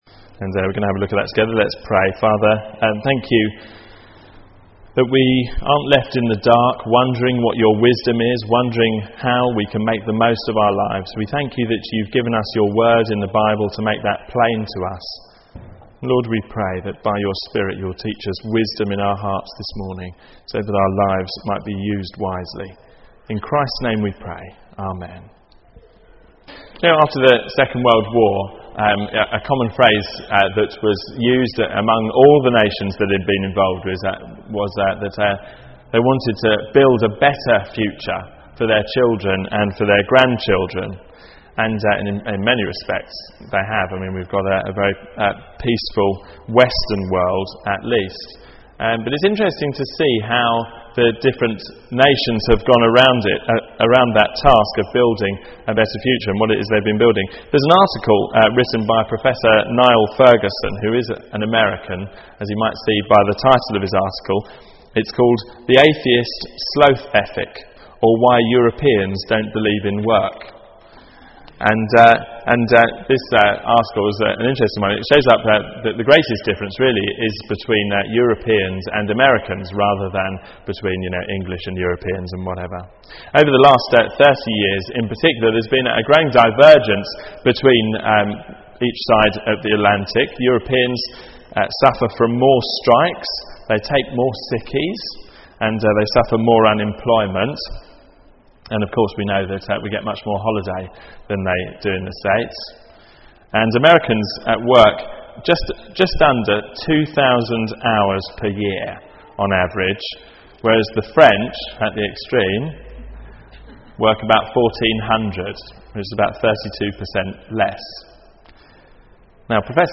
What your papa should have told you Theme: How to get the most out of life Sermon Search